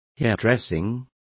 Complete with pronunciation of the translation of hairdressing.